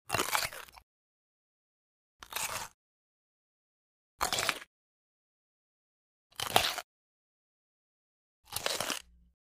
На этой странице вы найдете коллекцию звуков укуса — резких, комичных и ярких, как в мультфильмах.